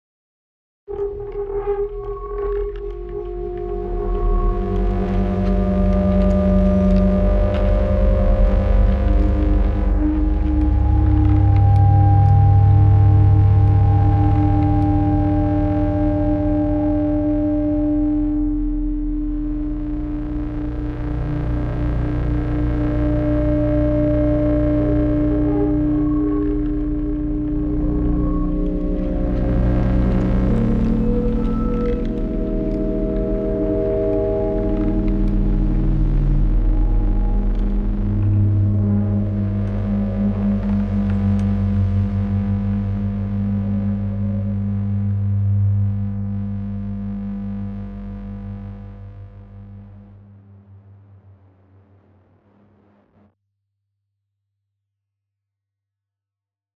Deep Sound Melange